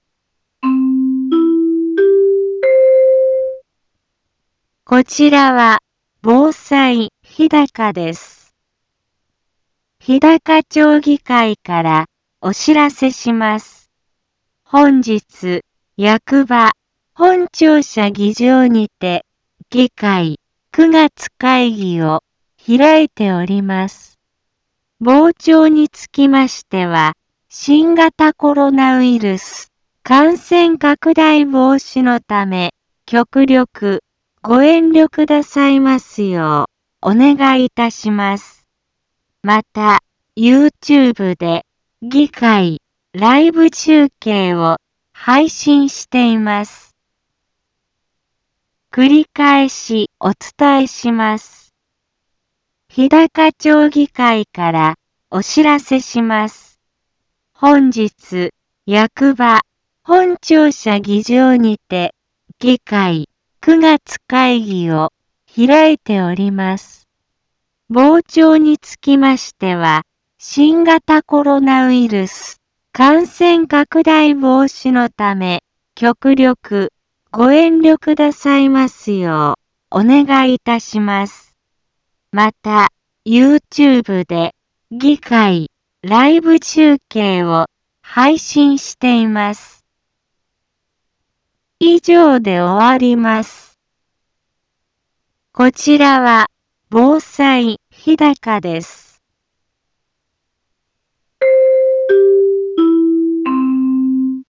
Back Home 一般放送情報 音声放送 再生 一般放送情報 登録日時：2021-09-08 10:03:41 タイトル：日高町議会９月会議のお知らせ インフォメーション：こちらは防災日高です。